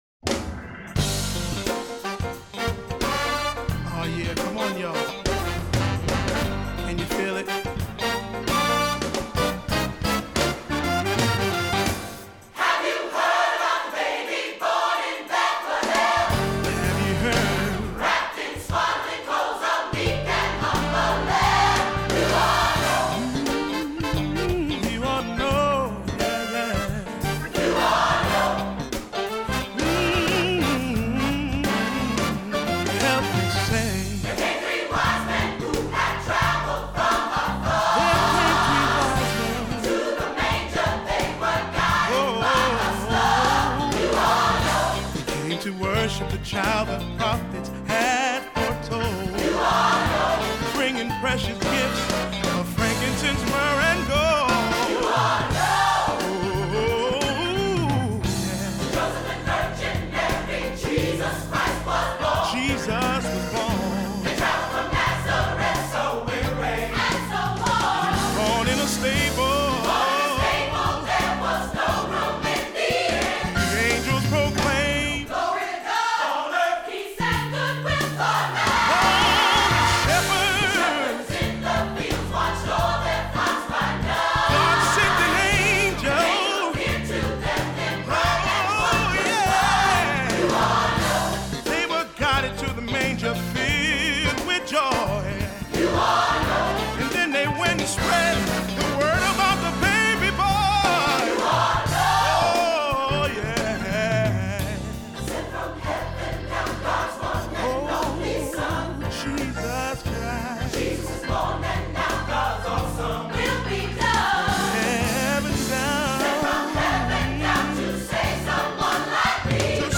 Youth Choir mp3's are now avaiable to download.